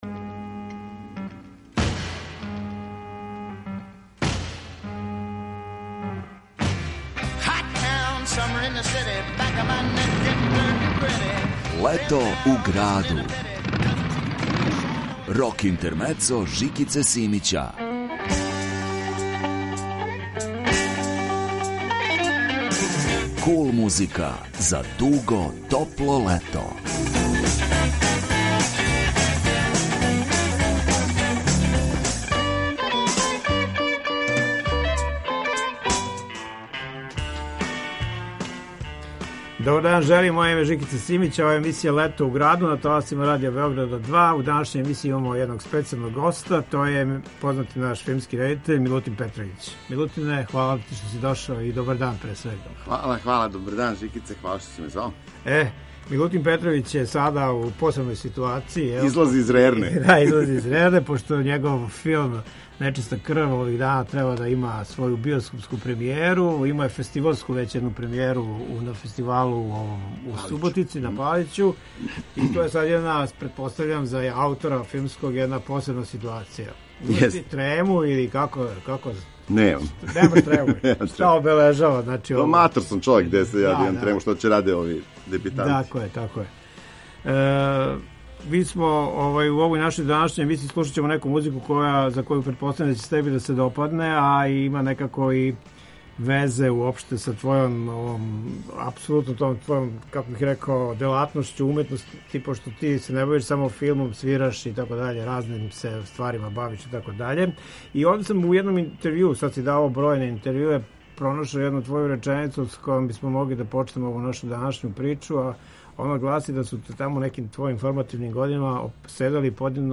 Kул музика за дуго топло лето.